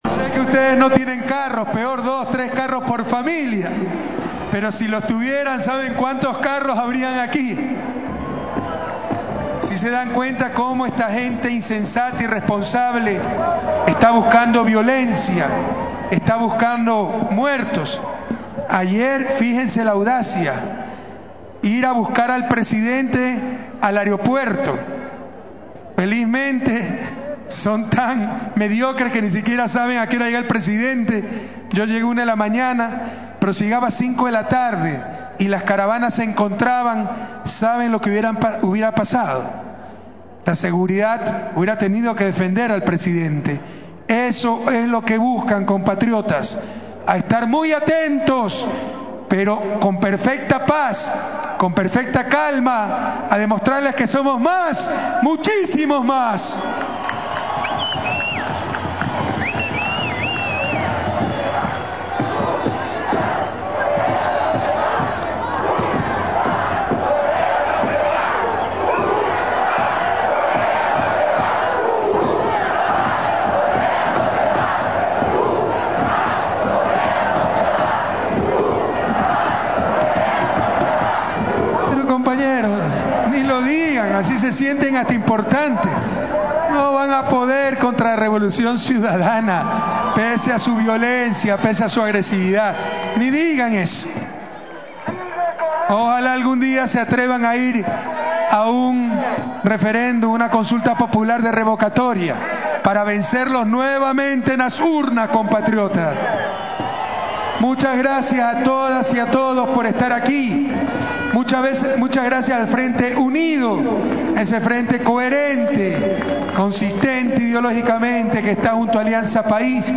En un efusivo discurso pronunciado ante miles de personas en el balcón del palacio presidencial, el jefe de Estado defendió las dos propuestas de ley, que están en trámite en la Asamblea Nacional, y cuestionó la campaña de desinformación orquestada por los opositores, que defienden el interés de menos del 2% de la población más acaudaladas y a los que especulan con las tierras, según ha denunciado el gobierno.
discursocorrea.mp3